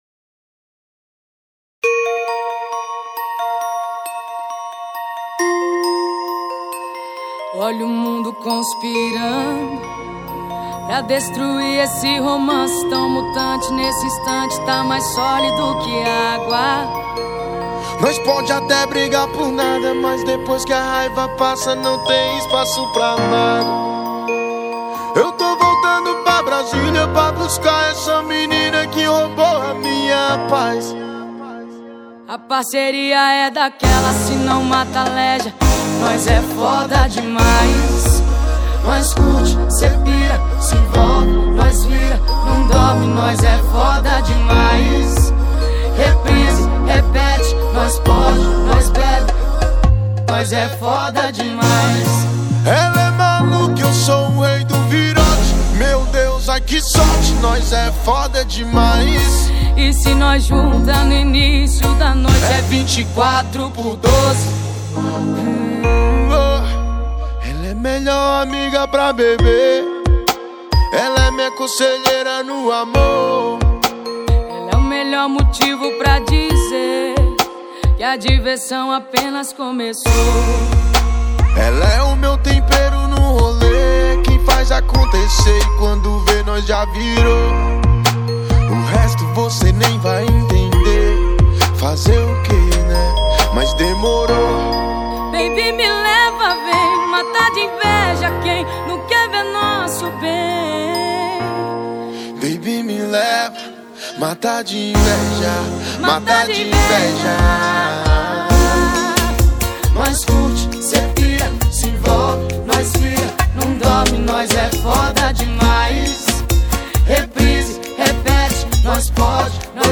2025-01-26 20:38:13 Gênero: MPB Views